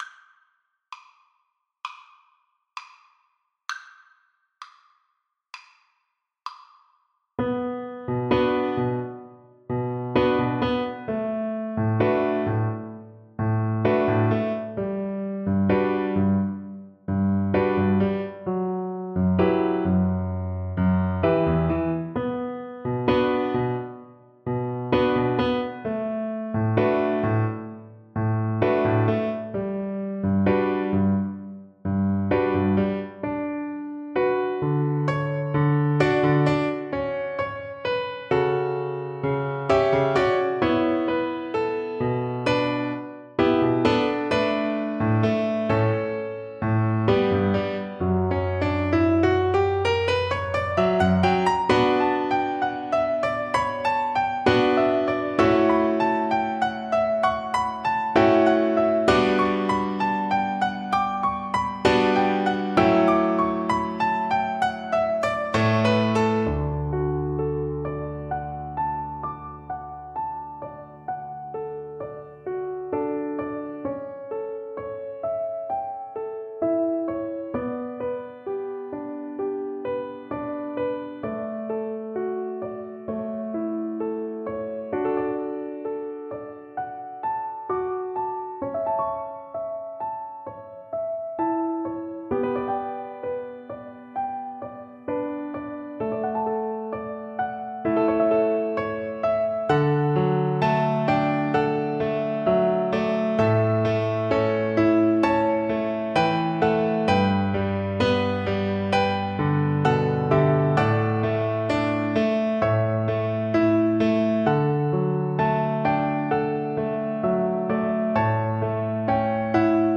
Mournfully but with energy = 100
4/4 (View more 4/4 Music)
Classical (View more Classical Violin Music)